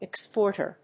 音節ex・pórt・er 発音記号・読み方
/‐ṭɚ(米国英語), ‐tə(英国英語)/